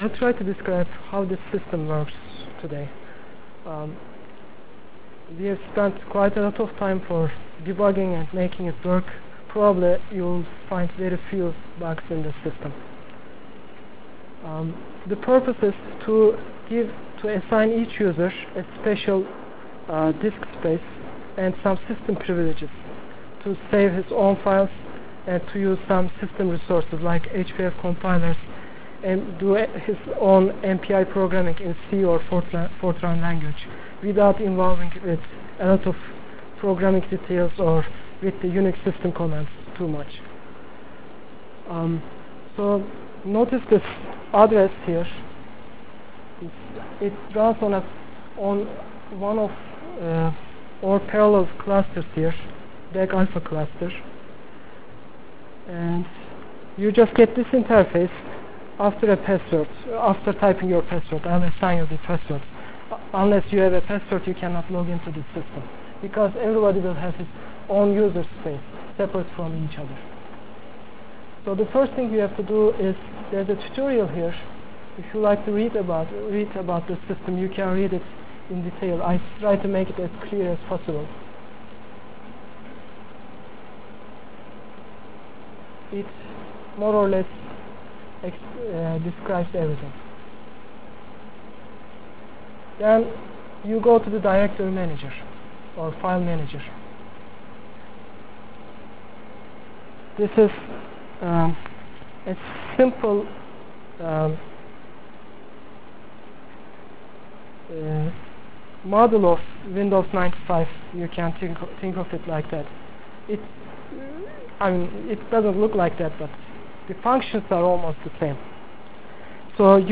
Foil 1 Delivered Lectures for CPS615 -- Base Course for the Simulation Track of Computational ScienceFall Semester 1996 --Lecture of September 26 - 1996